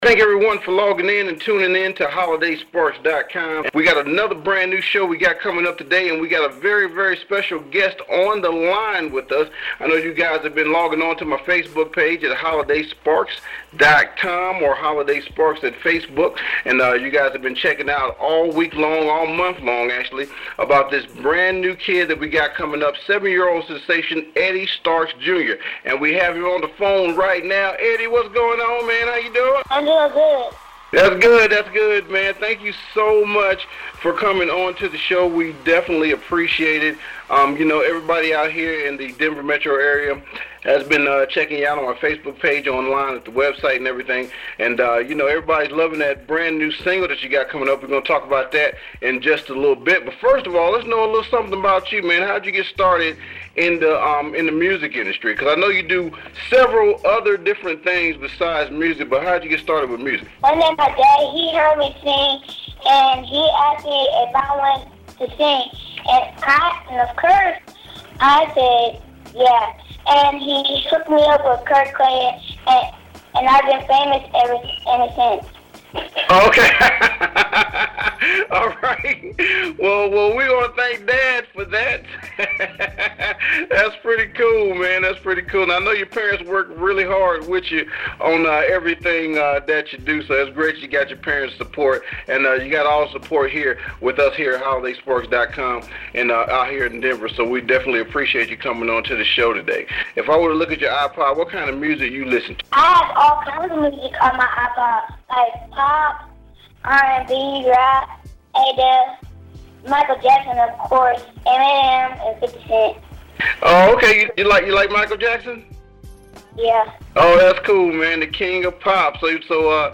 My First Radio Interview